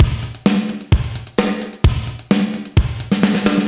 Index of /musicradar/80s-heat-samples/130bpm
AM_GateDrums_130-02.wav